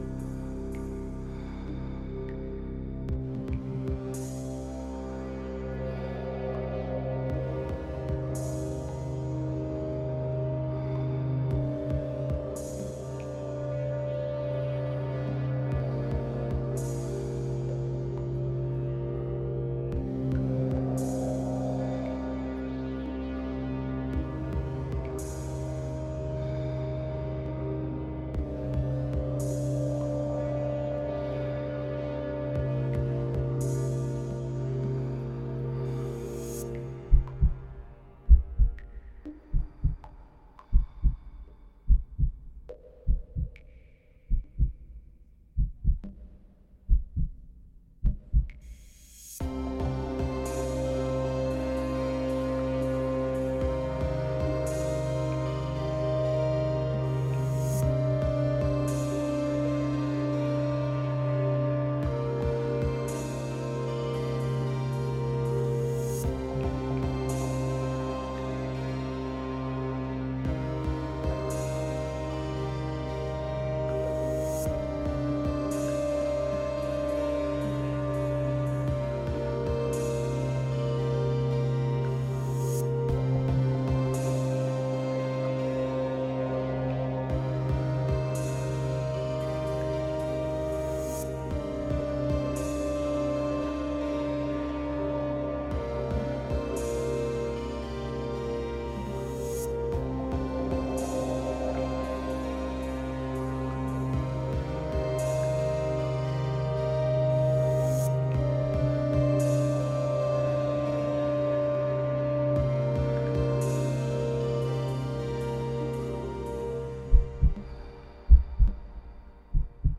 Intervista Morgue Ensemble a Puzzle 5-4-2021